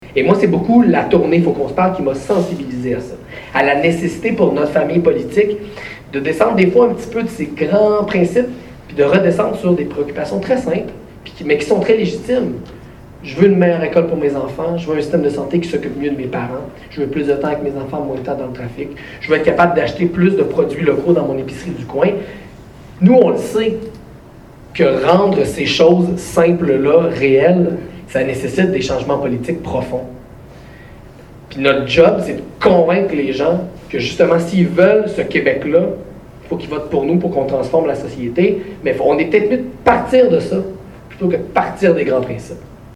en entrevue avec Gabriel Nadeau-Dubois.